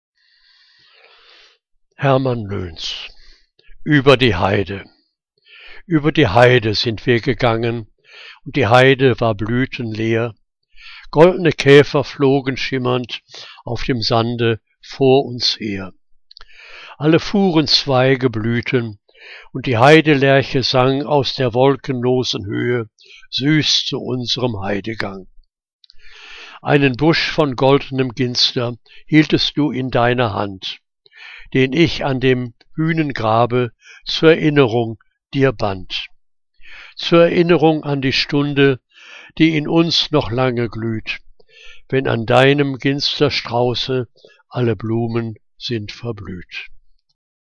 Liebeslyrik deutscher Dichter und Dichterinnen - gesprochen (Hermann L�ns)